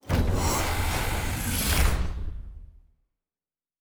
pgs/Assets/Audio/Sci-Fi Sounds/Doors and Portals/Door 1 Close 1.wav at 7452e70b8c5ad2f7daae623e1a952eb18c9caab4
Door 1 Close 1.wav